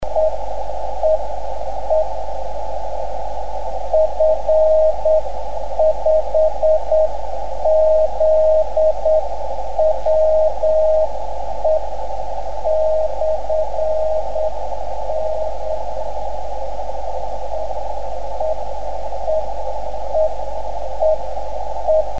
10m Baken
Die hier aufgeführten Stationen wurden selbst empfangen.